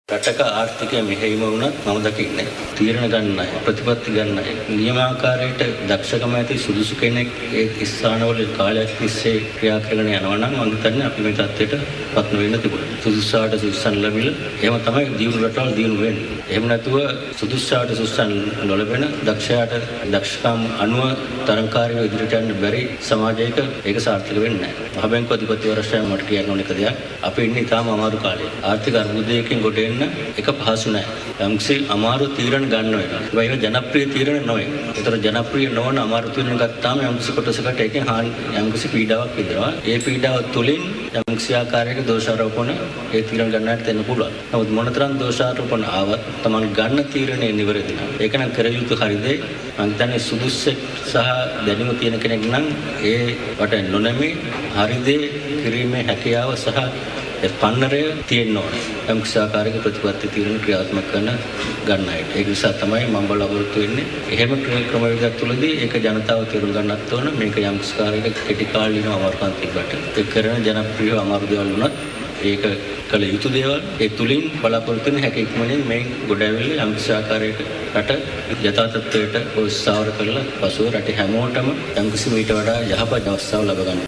මාතර ප්‍රදේශයේ පැවති උත්සවයක් අමතමින් මහබැංකු අධිපතිවරයා කියා සිටියේ පවතින ආර්ථික අර්බුදයෙන් රට ගොඩ ගැනීම සදහා තමන් ගන්නා අපහසු තීන්දු තීරණ සම්බන්ධයෙන් ජනතාව අබෝධයෙන් යුතුව ක්‍රියා කරනු ඇති බවයි.